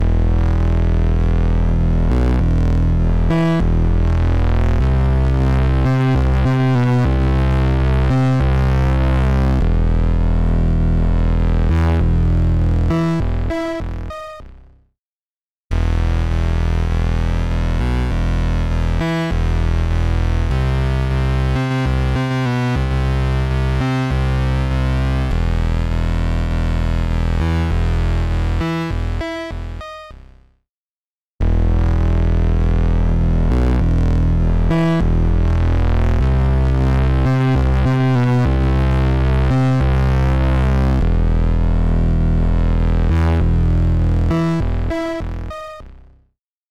Invigorate | Bass Synth | Preset: Bass Synth Growler
Invigorate-Bass-Synth-Bass-Synth-Growler-CB.mp3